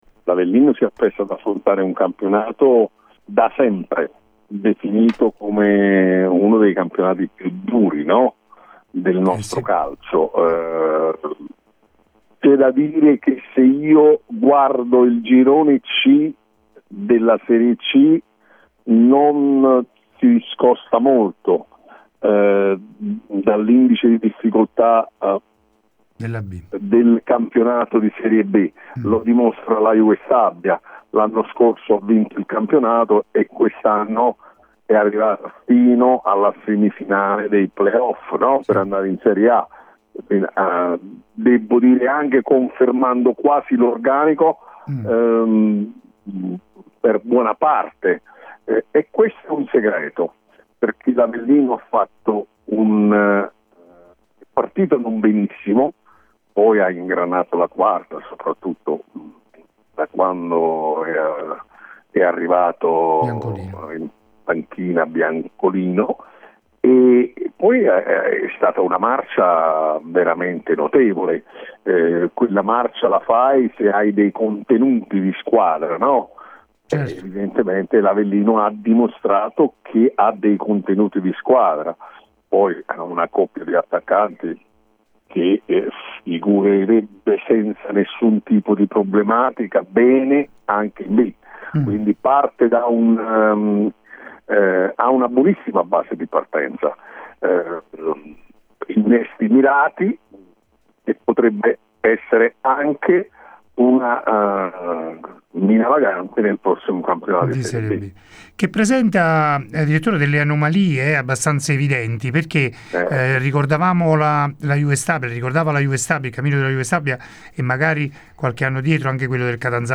Ospite de Il Pomeriggio da Supereroi di Radio Punto Nuovo il direttore sportivo Pietro Lo Monaco. Diversi i temi di discussione, a partire dal ritorno in Serie B dell’Avellino fino alle differenze tra la cadetteria e la Serie C. Sullo sfondo